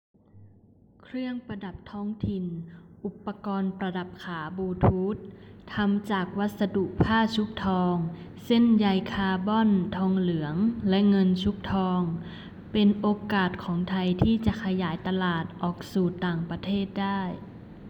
★実際にニュースを読む上げたものはこちら。